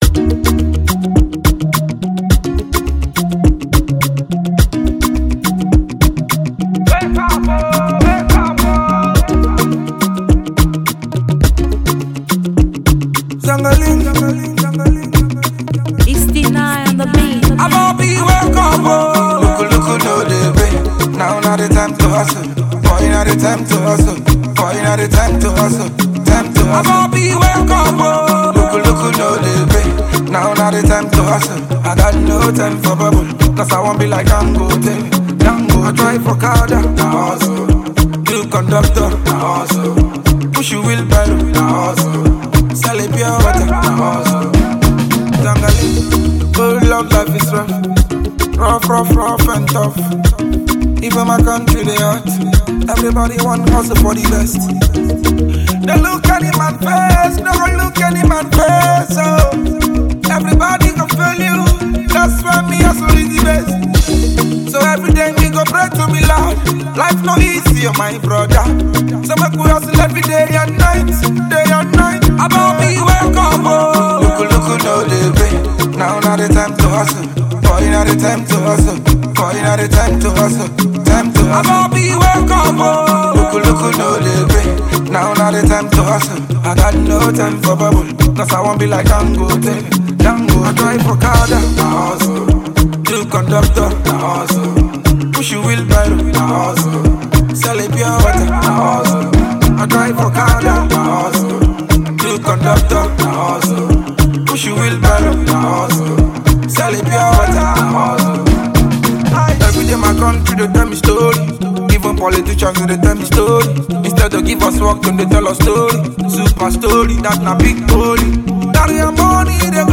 Topflight Nigeria Afro hip hop singer